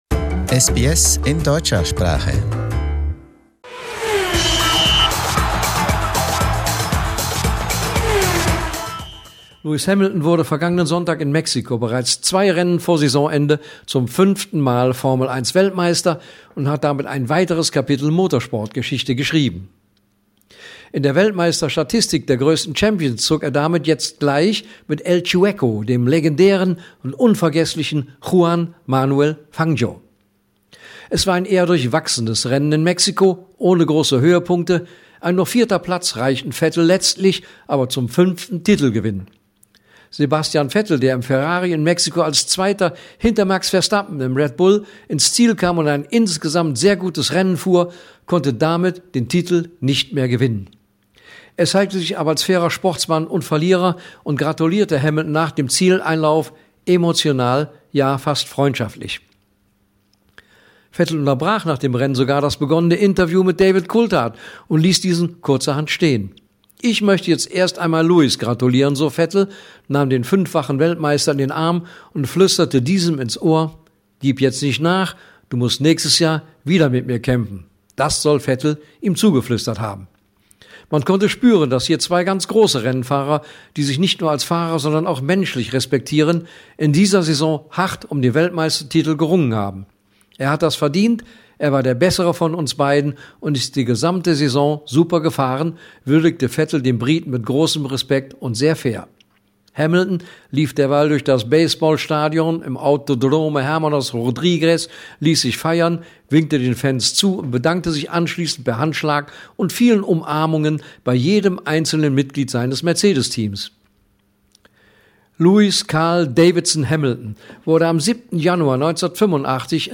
Formel 1 Sonderbericht: Lewis Hamilton schrieb Motorsportgeschichte